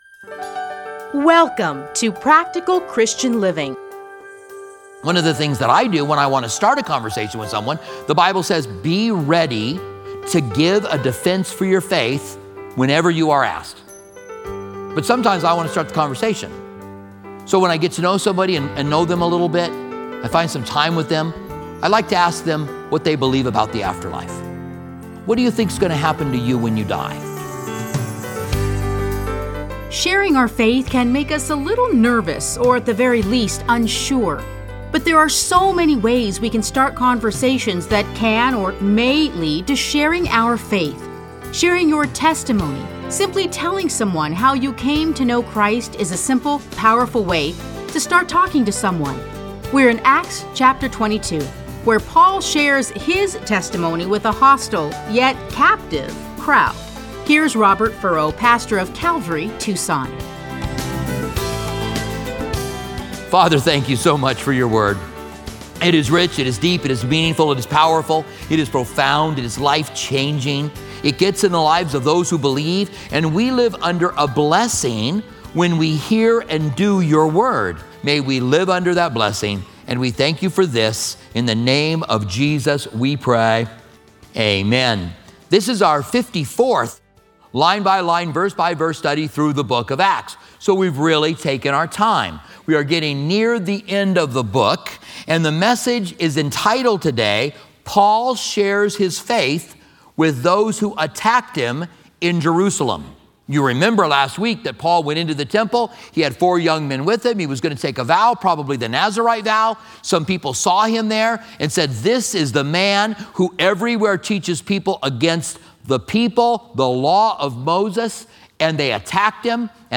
Listen to a teaching from Acts 22.